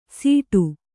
♪ sīṭu